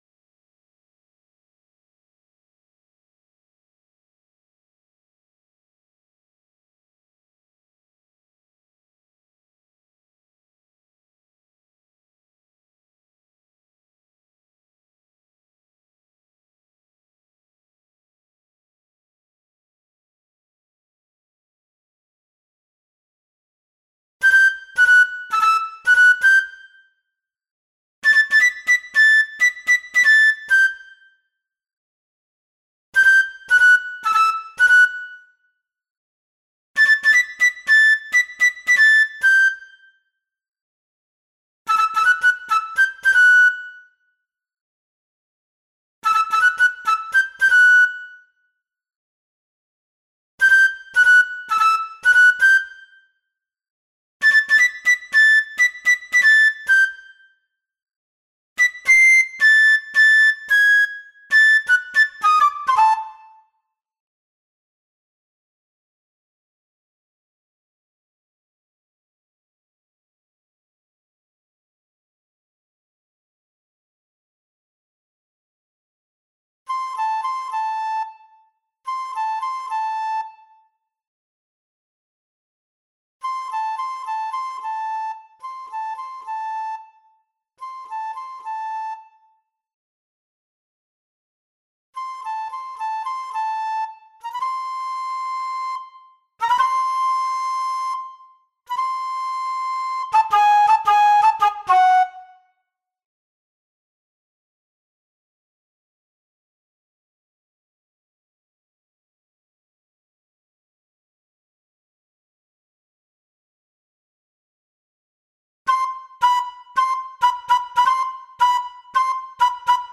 Sing3-v1-Fl2.mp3